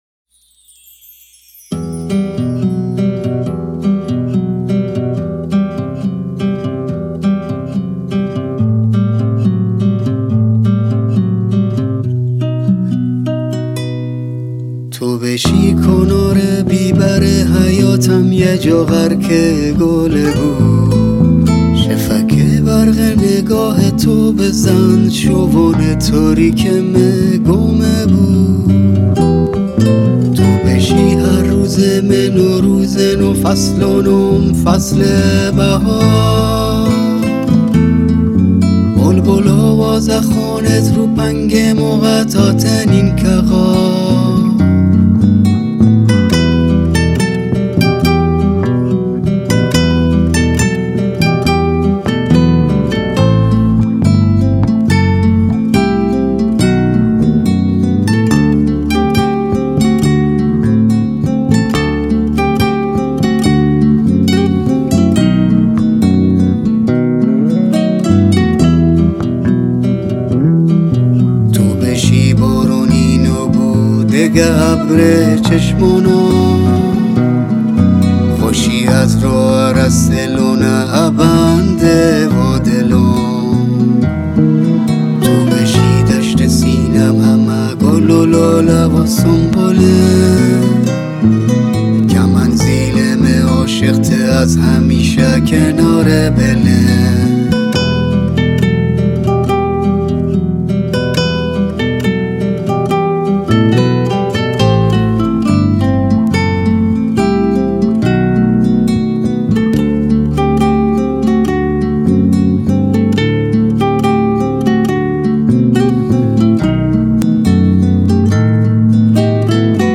🎸 گیتار و اوکه‌له‌له
🎸 گیتار باس